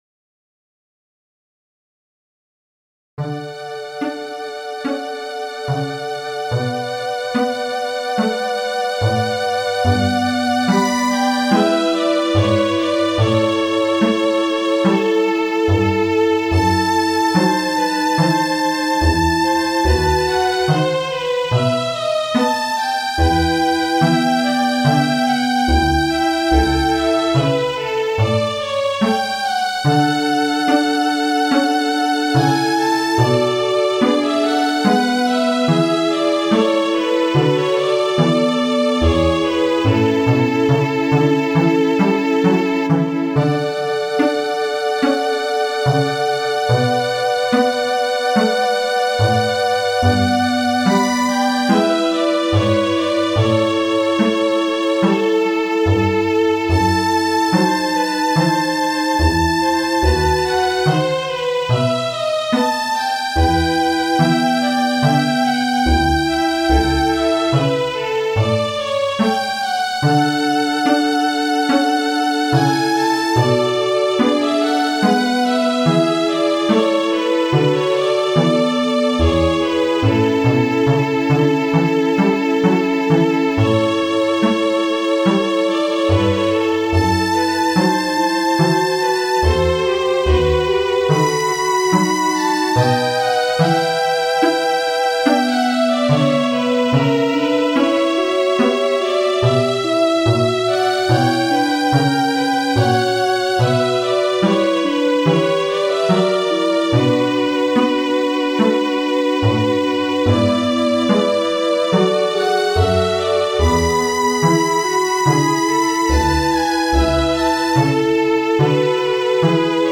The above MIDI link is to the 'Air' for the Suite in D by Bach. It uses a key signature of two sharps (D major).